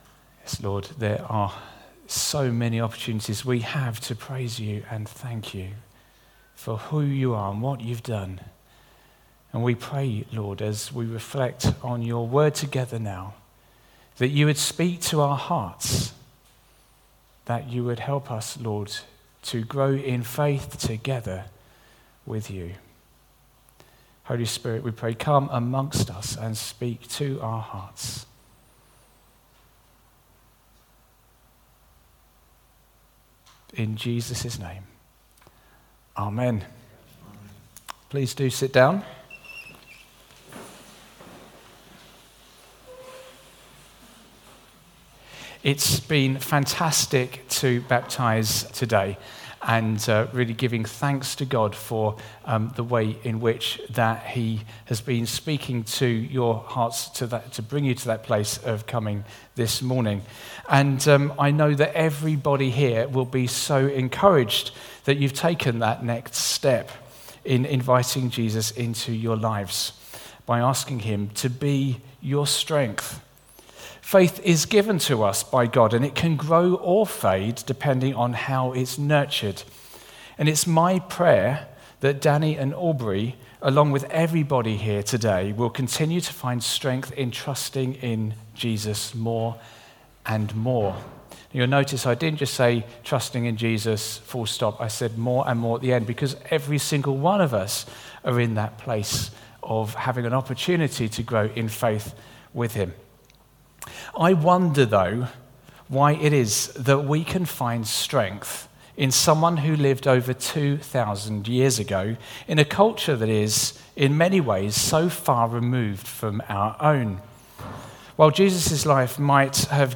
Sermon 15th Oct 2023 11am gathering
We have recorded our talk in case you missed it or want to listen again.